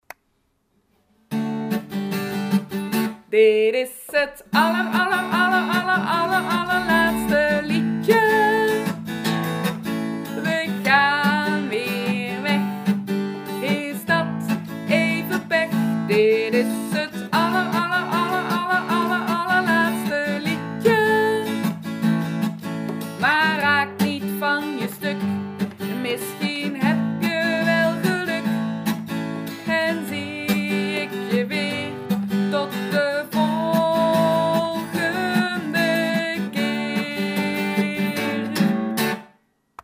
openings- en slotliedjes van de muziekles